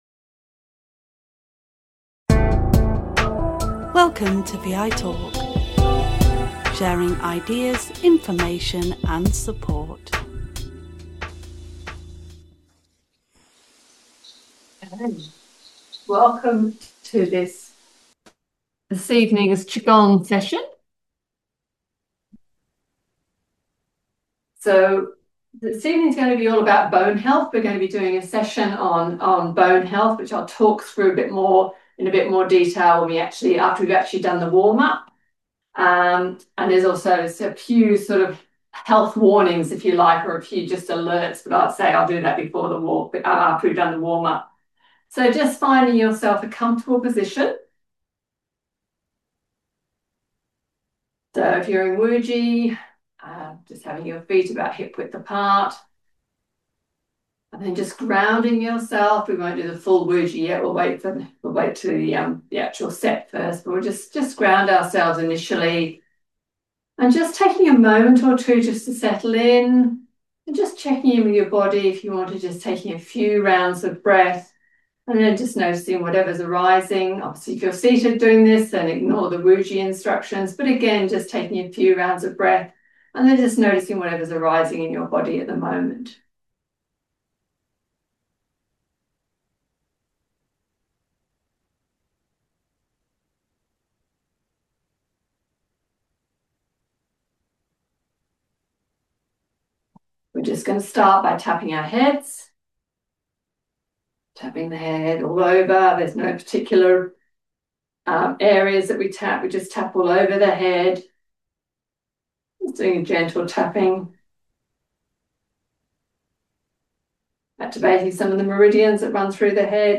QiGong is gentle, mindful movement which can be done either seated or standing. The sessions take place over Zoom on the first Monday of the month at 8 pm.